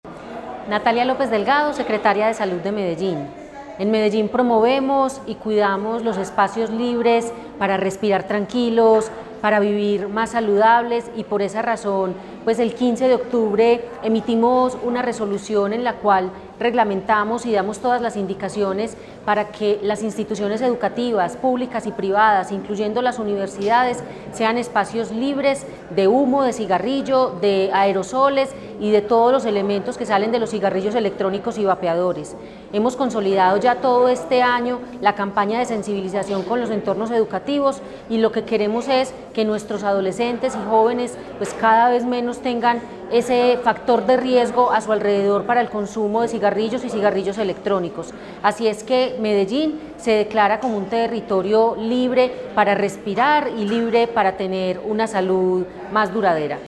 Declaraciones de la secretaria de Salud, Natalia López Delgado
Declaraciones-de-la-secretaria-de-Salud-Natalia-Lopez-Delgado-2.mp3